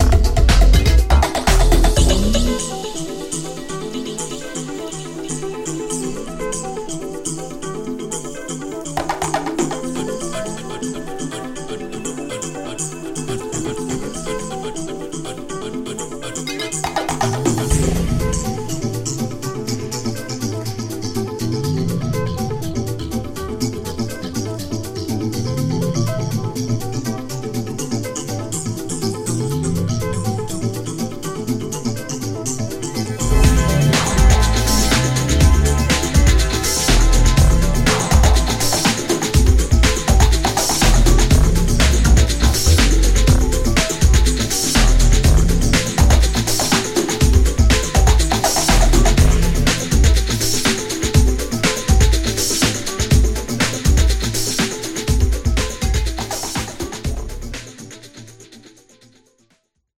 Breakbeat , Electro , Leftfield , Tribal